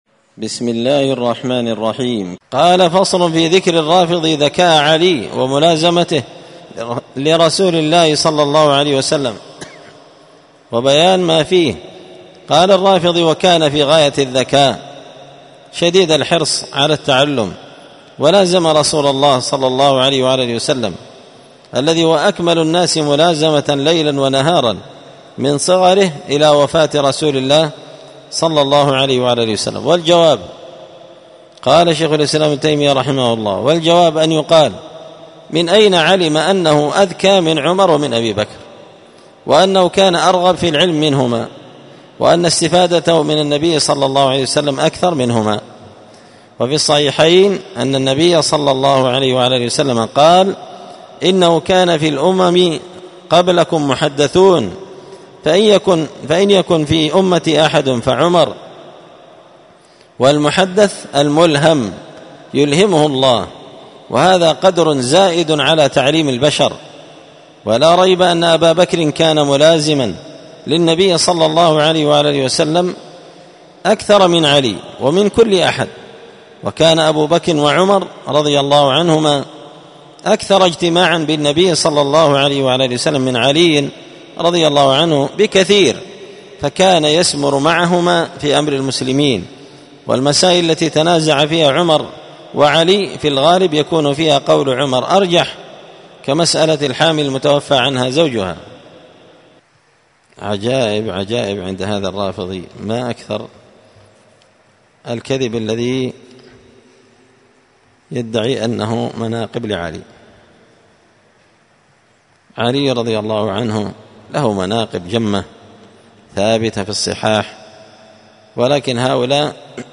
الدرس الثامن بعد المائتين (208) فصل في ذكر الرافضي ذكاء علي وملازمته لرسول الله وبيان مافيه
مسجد الفرقان قشن_المهرة_اليمن